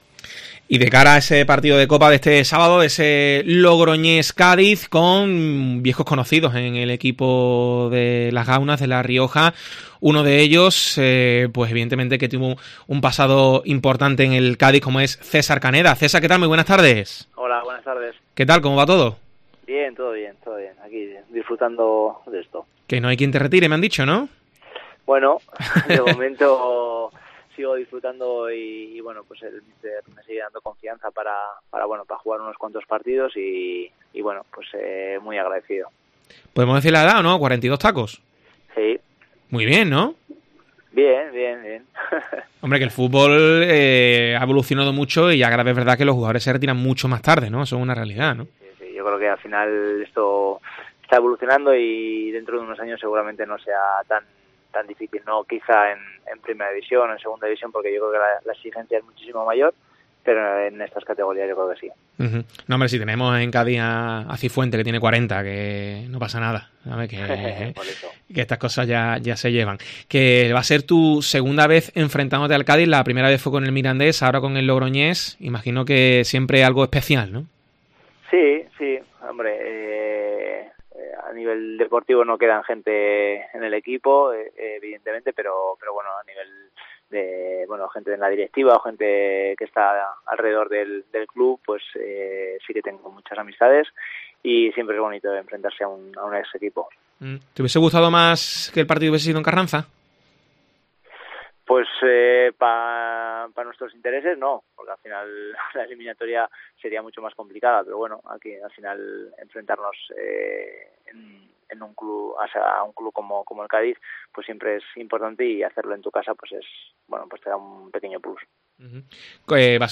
AUDIO: Entrevista al exjugador del Cádiz